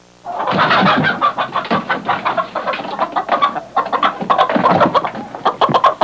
Hen Sound Effect
Download a high-quality hen sound effect.
hen.mp3